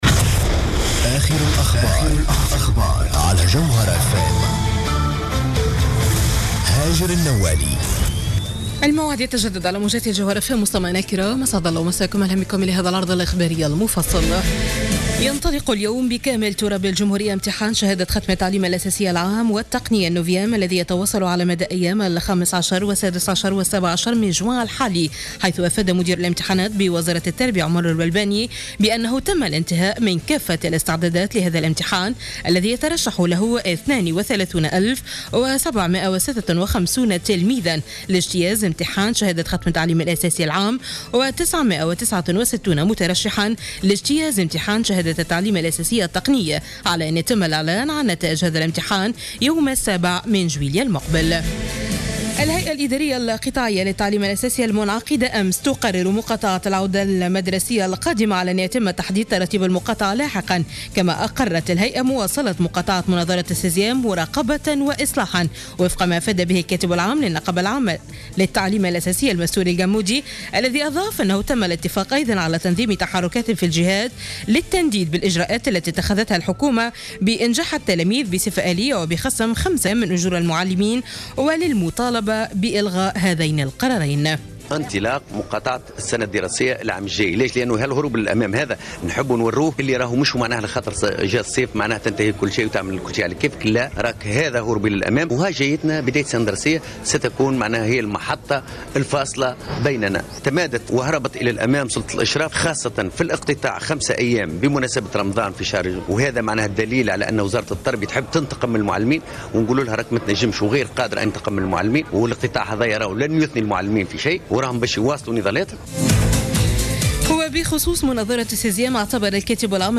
نشرة أخبار منتصف الليل ليوم الإثنين 15 جوان 2015